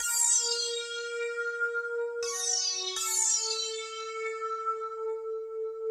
01F-SYN-.A-L.wav